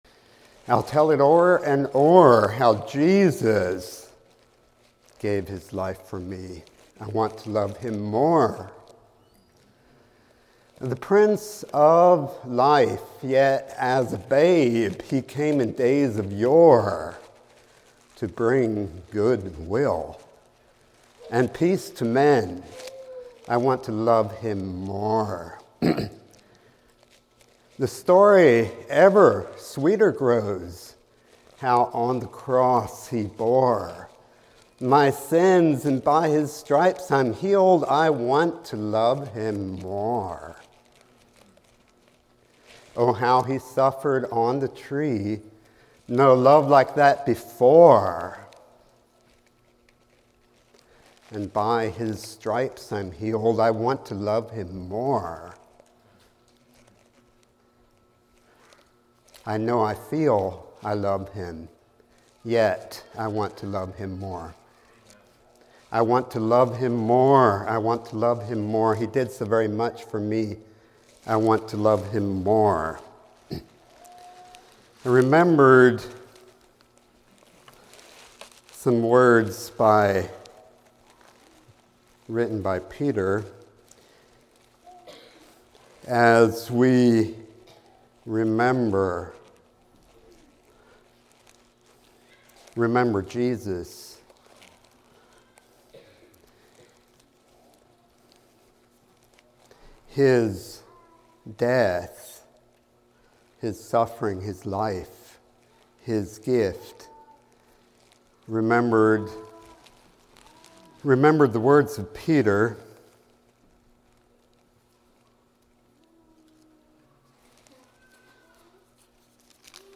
A message from the series "2025 Messages."